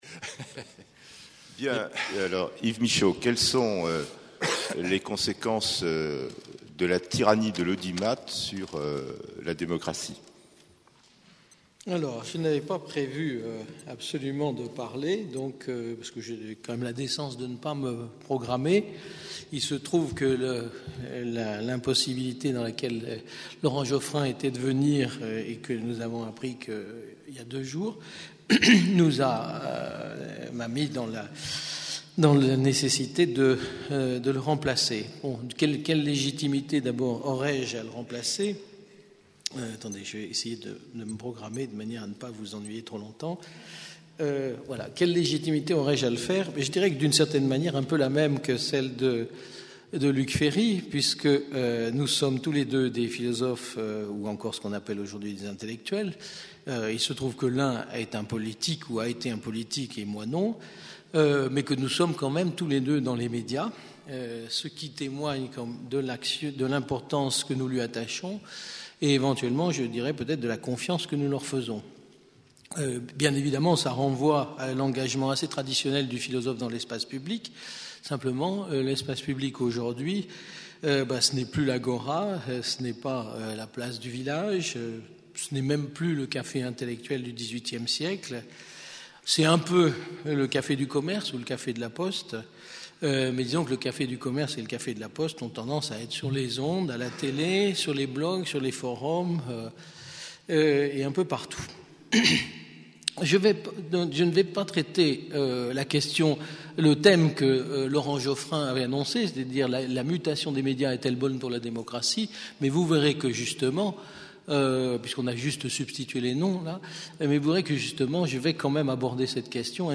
Premiers entretiens du jeu de paume